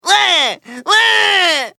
Quagmire Crying Sound Effect Free Download
Quagmire Crying